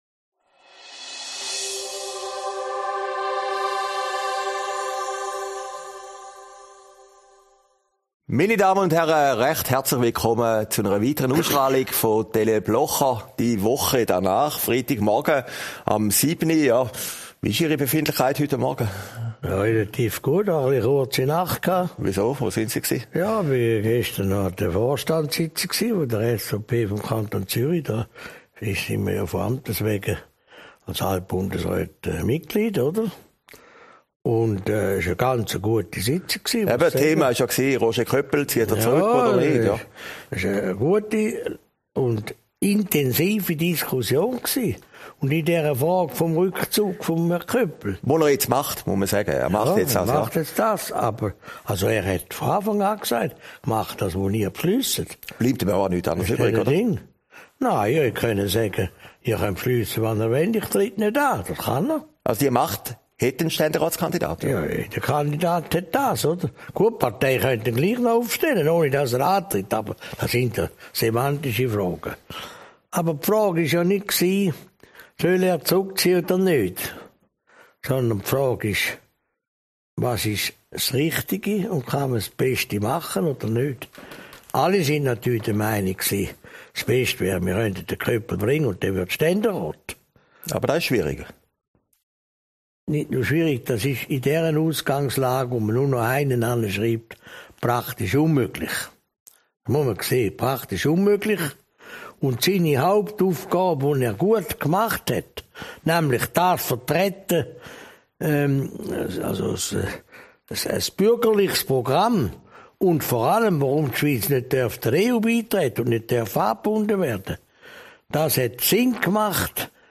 Aufgezeichnet in Herrliberg, 25. Oktober 2019